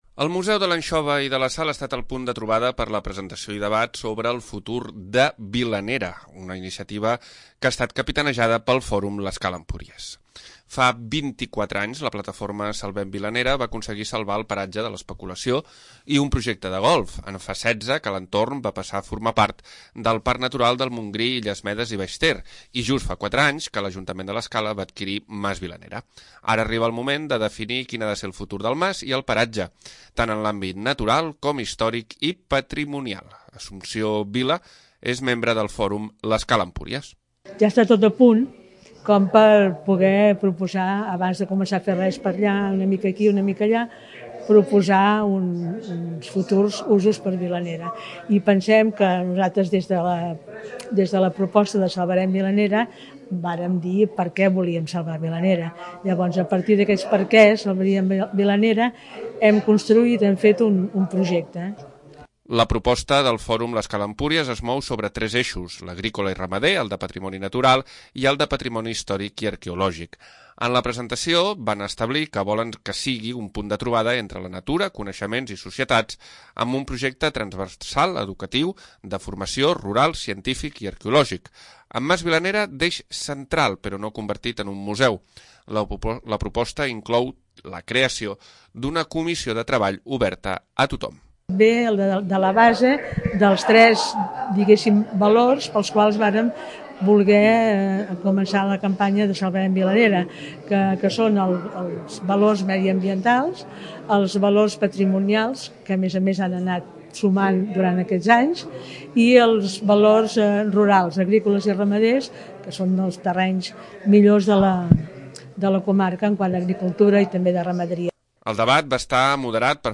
El Museu de l'Anxova i de la Sal ha estat el punt de trobada per la presentació i debat sobre el futur de Vilanera, una iniciativa que ha estat capitanejada pel Fòrum l'Escala-Empúries.